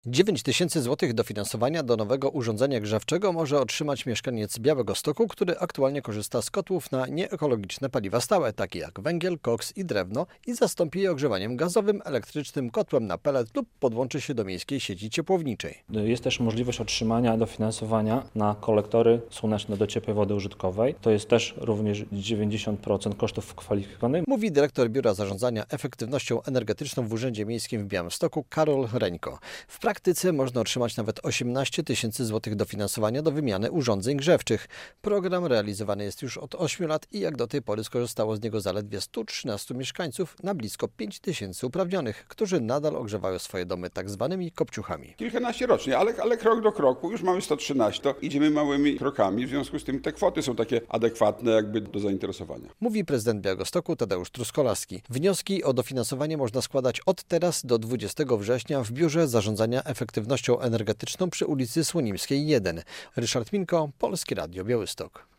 Radio Białystok | Wiadomości | Wiadomości - Chcesz wymienić stary piec?